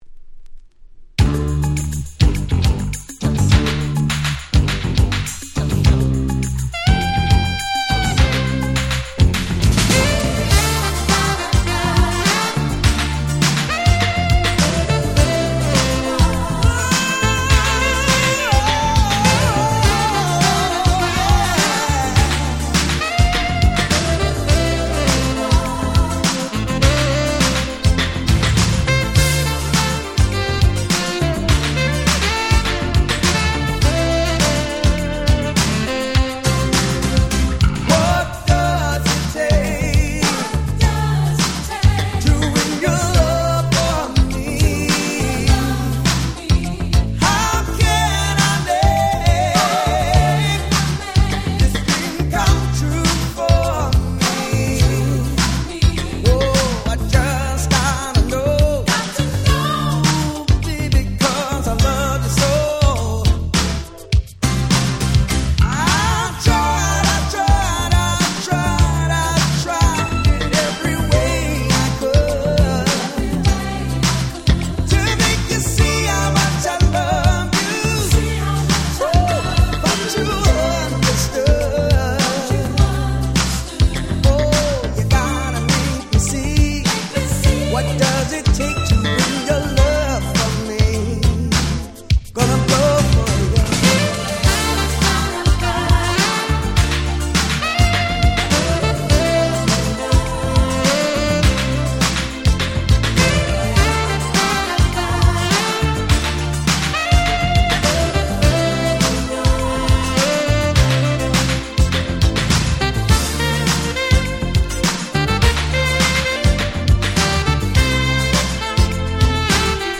86' Smash Hit Fusion / R&B !!
相変わらず謎にムーディー！！(笑)
謎にRun DMCっぽいBeatも凄く良い！(笑)